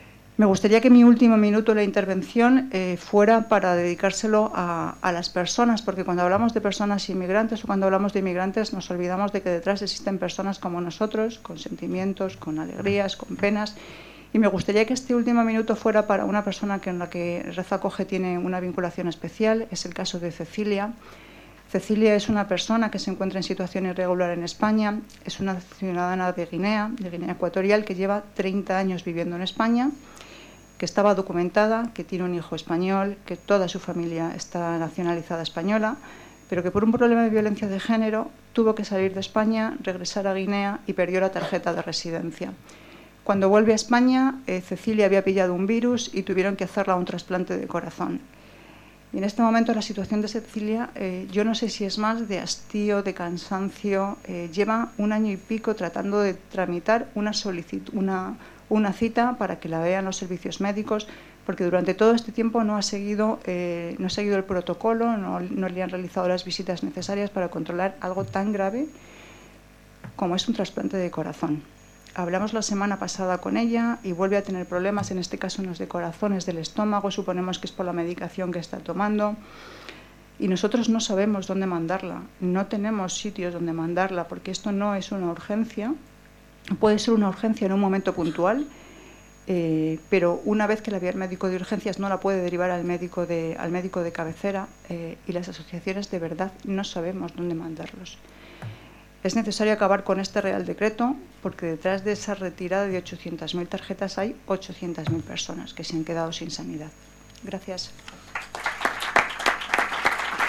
Un año de recortes en Sanidad. Jornada organizada en el Congreso con médicos y expertos.